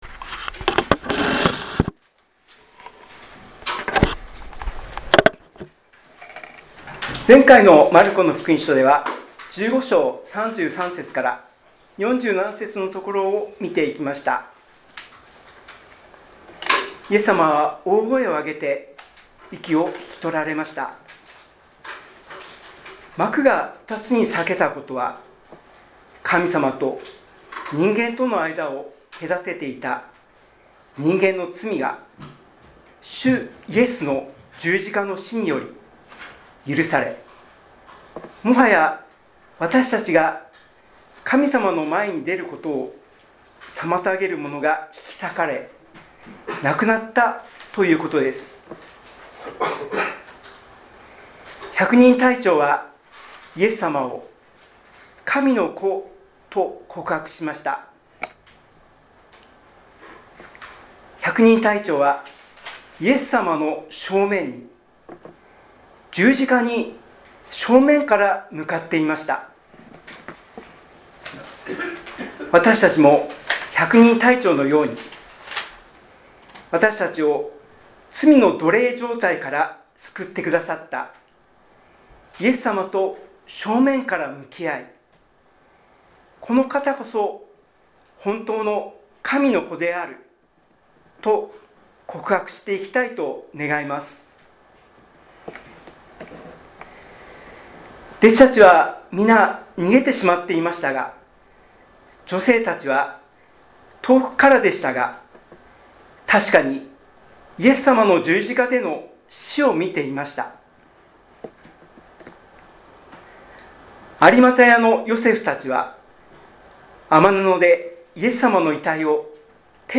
礼拝メッセージ – コイノニアキリスト教会